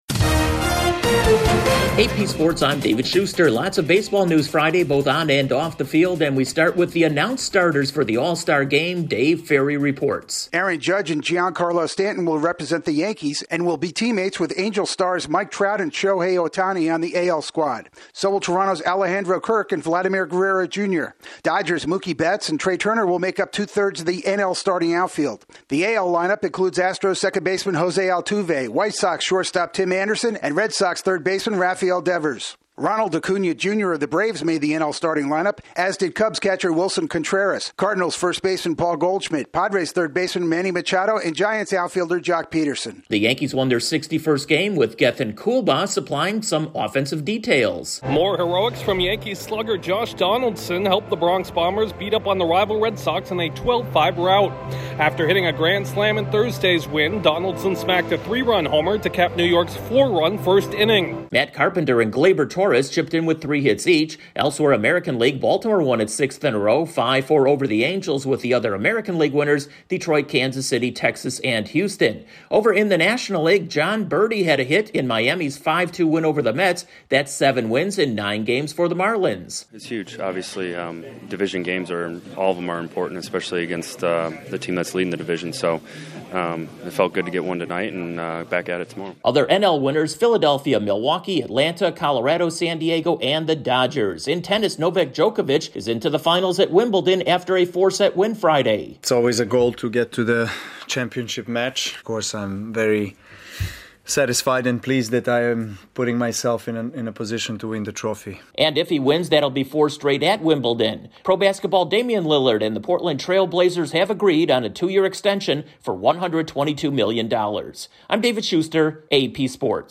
MLB announces the starters for the All-Star game, the Yankees keep rolling, the Mets see their division lead cut to 2 1/2 games over the Braves and Novak Djokovic reaches his 32nd Grand Slam final. Correspondent